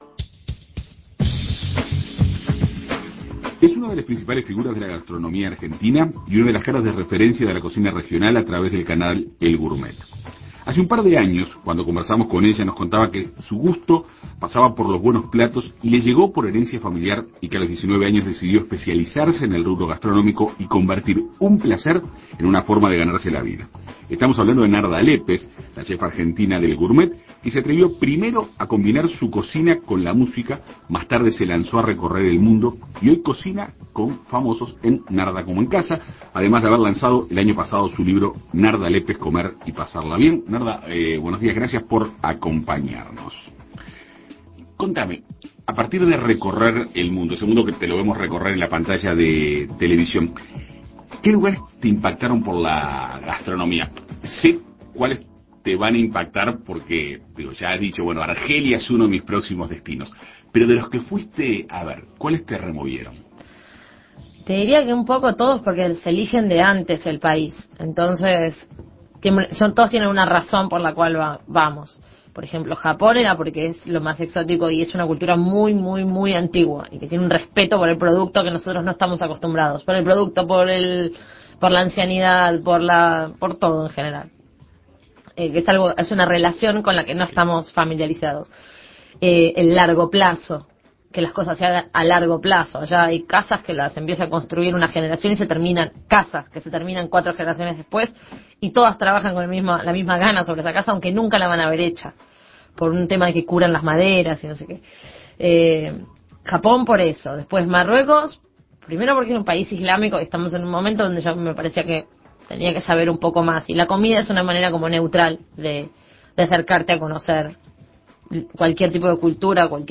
Entrevista con Narda Lepes, chef argentina de El Gourmet.